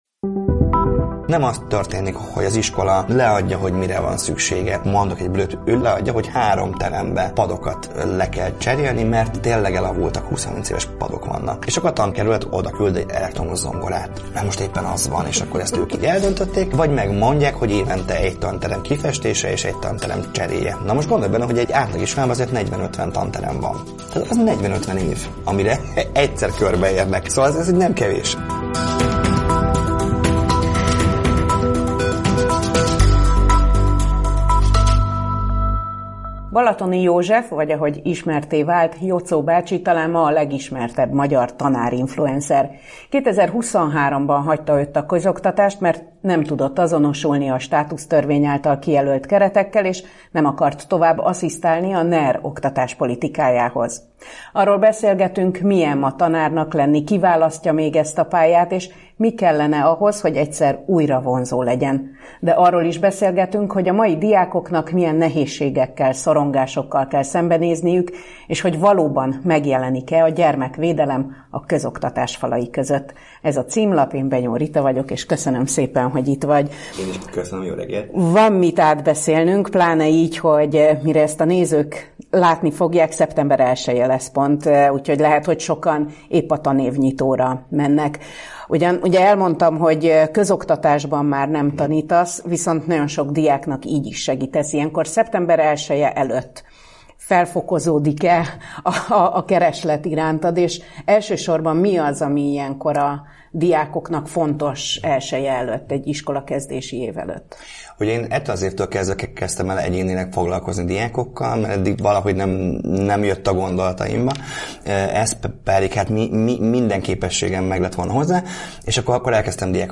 Tanévnyitó beszélgetés.”